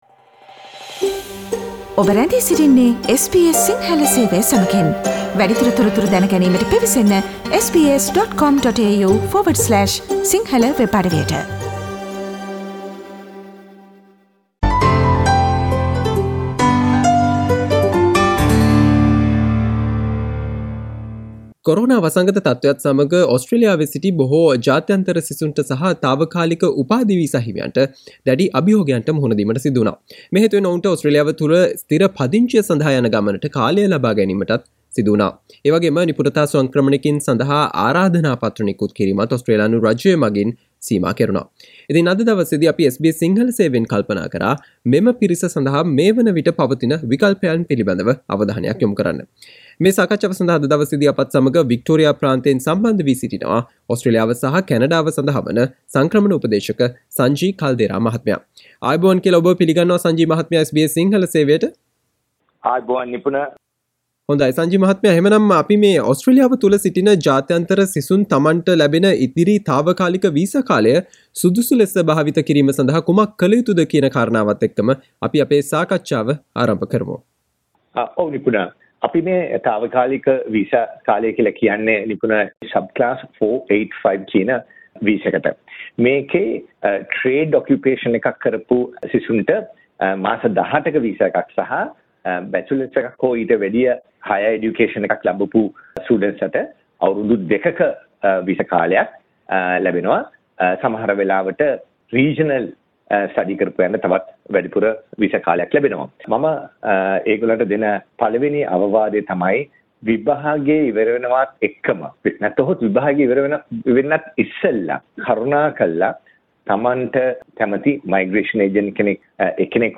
SBS සිංහල සේවය සිදුකළ සාකච්ඡාව.